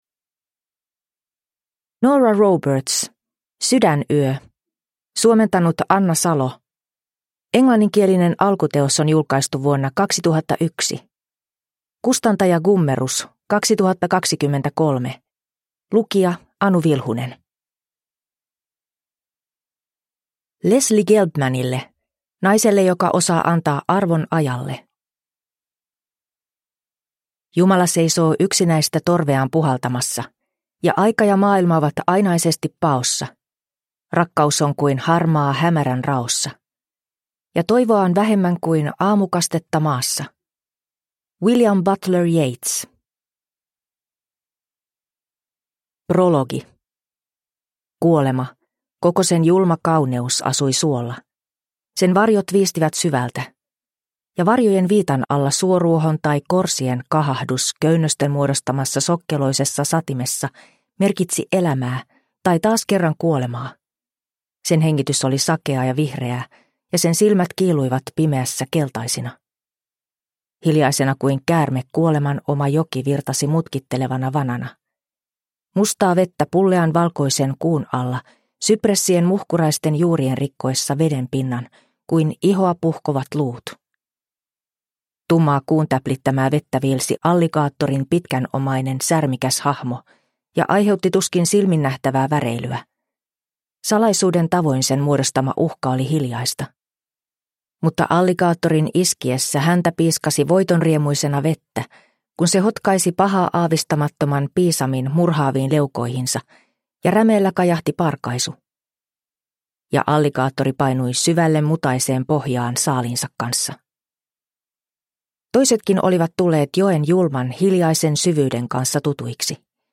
Sydänyö – Ljudbok – Laddas ner